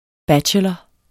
Udtale [ ˈbadɕəlʌ ]